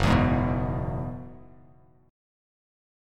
F#7sus2sus4 chord